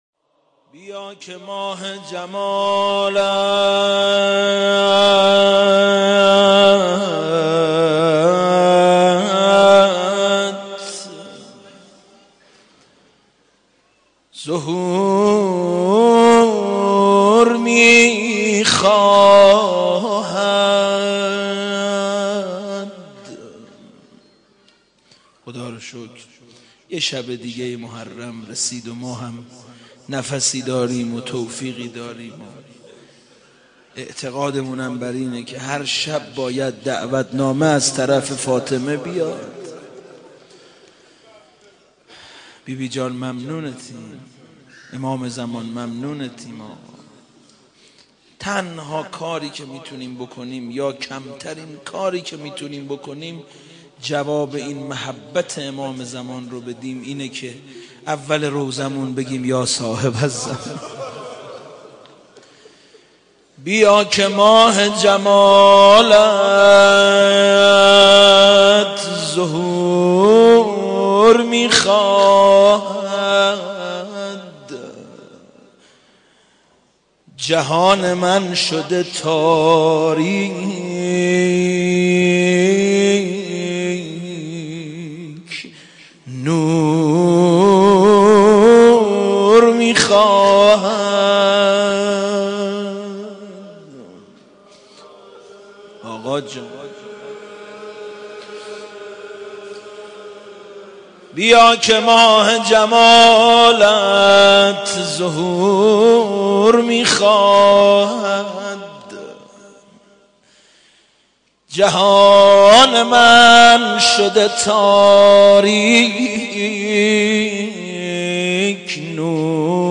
مناجات با امام زمان ع